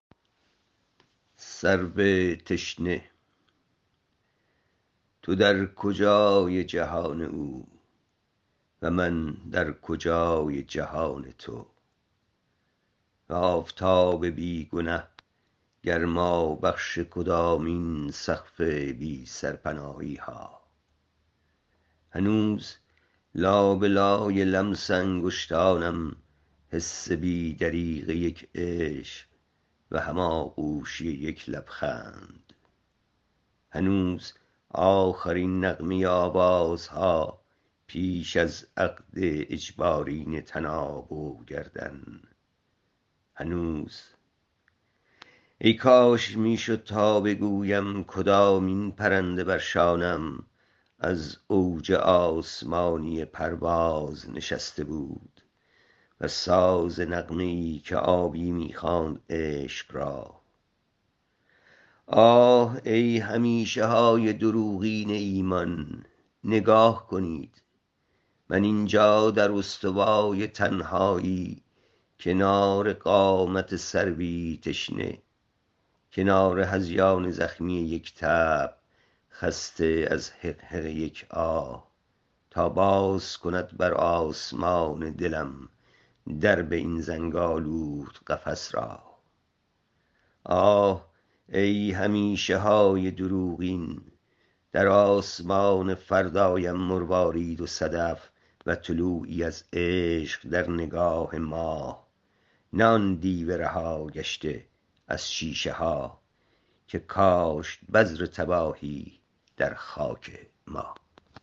این شعر را با صدای شاعر بشنیود